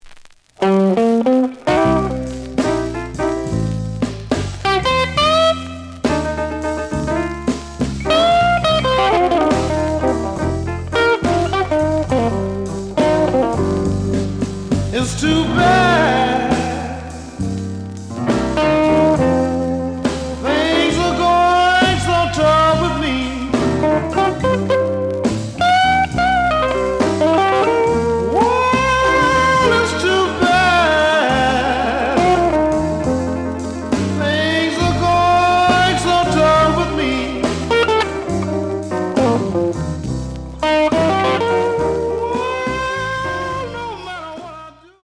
The audio sample is recorded from the actual item.
●Genre: Blues
Looks good, but slight noise on both sides.)